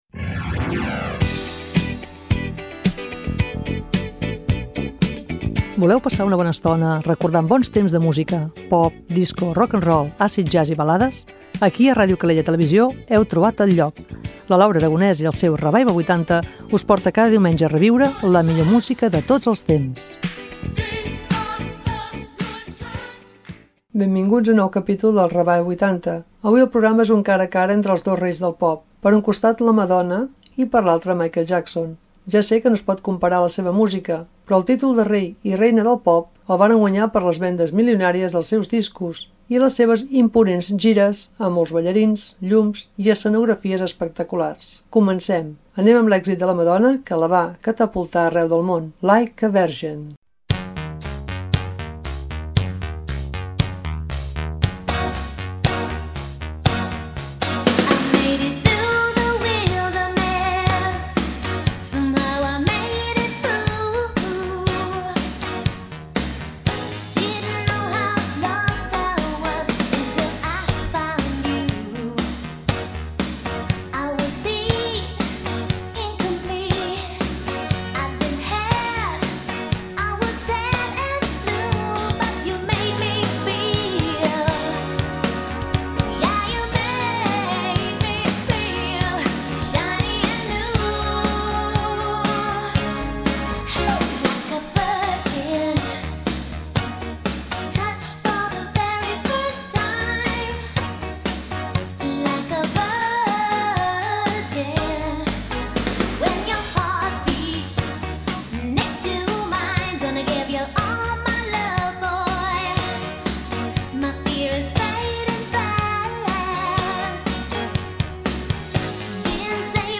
amb la reina i el rei indiscutible de la música pop